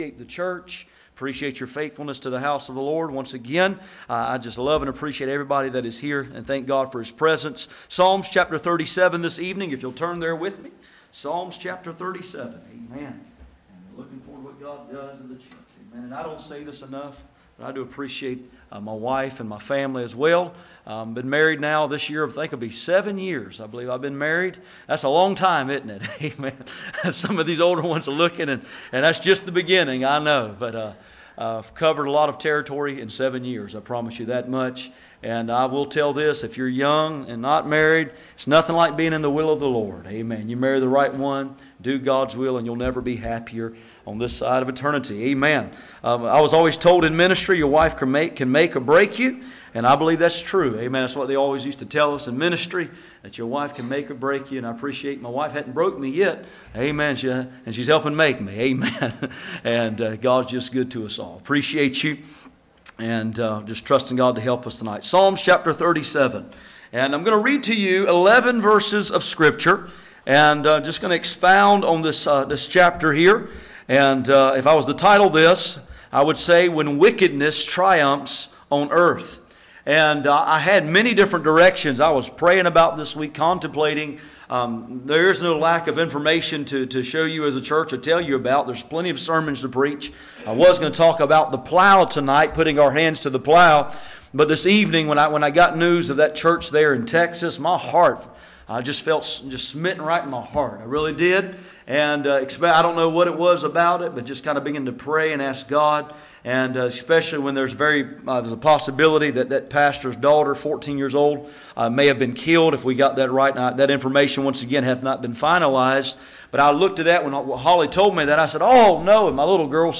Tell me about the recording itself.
None Passage: Psalm 11:1-7 Service Type: Sunday Evening %todo_render% « Amazing Unbelief The Sower